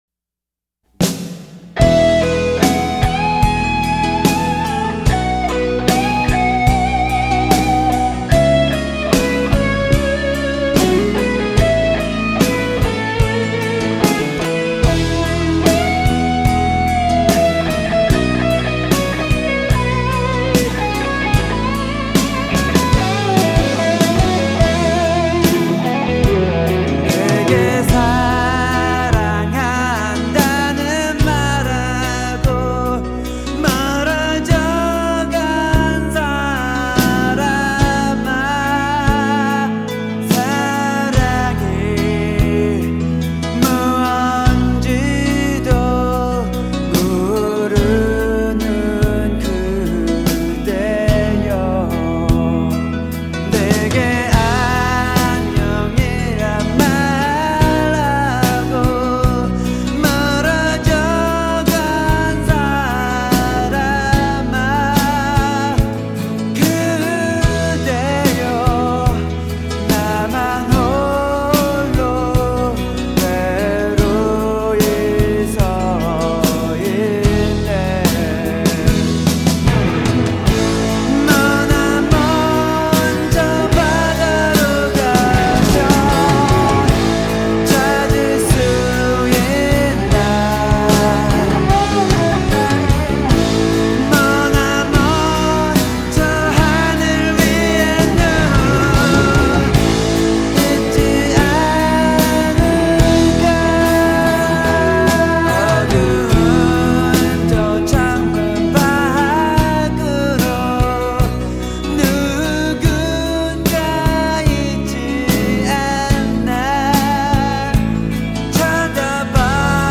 3인조 블루스 록 밴드
군더더기 없는 기타 멜로디와 가식 없는 창법이 매력적인 트랙이다.